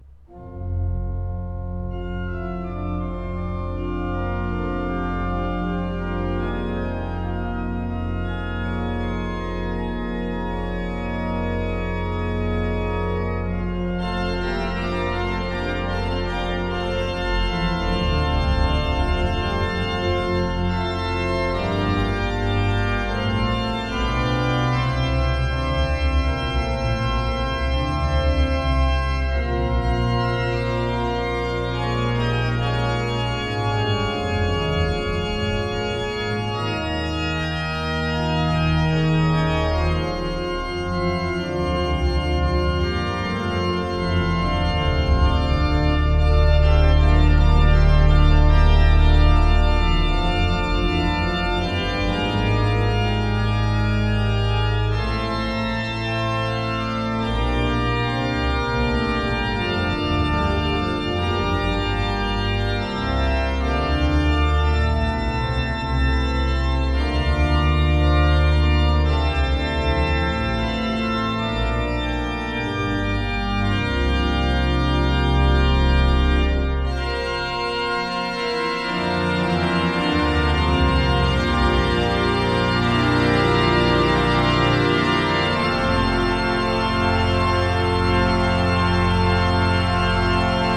Musica Sacra